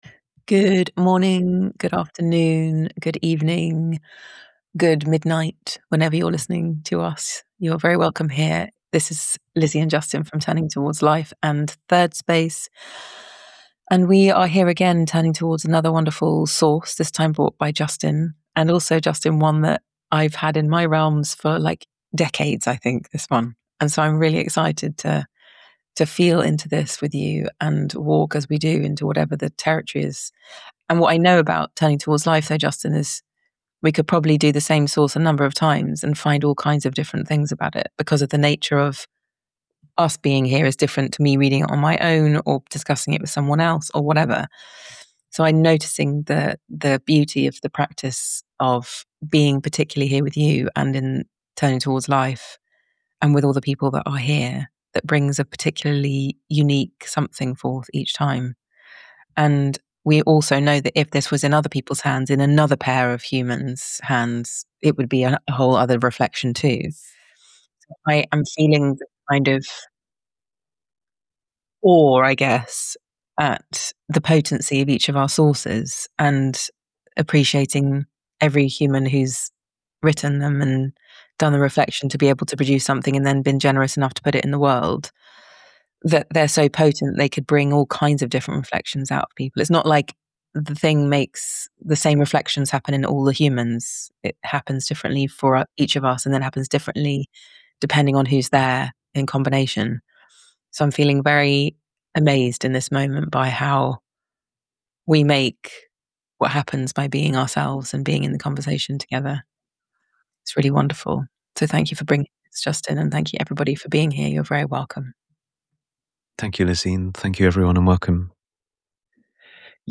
In our conversation we talk specifically about Turning Towards Life - Live - Season 2 , which begins in March 2026.